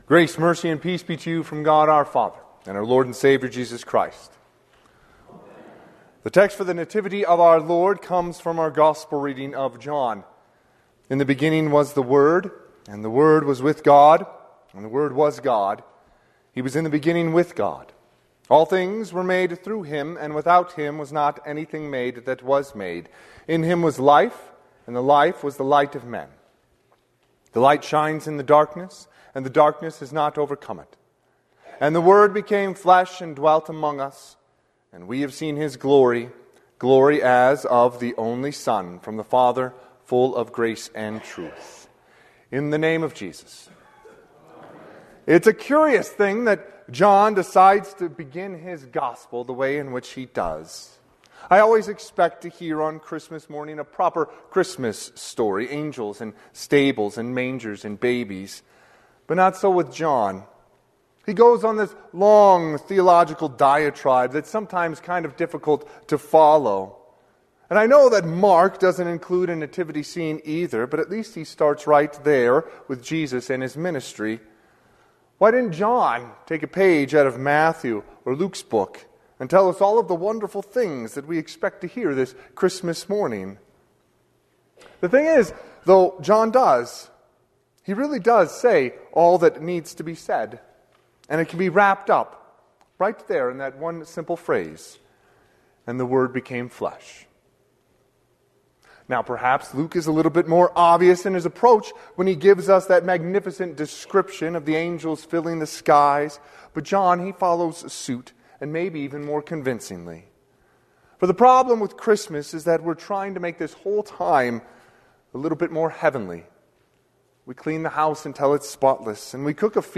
Sermon - 12/25/2024 - Wheat Ridge Lutheran Church, Wheat Ridge, Colorado
Christmas Day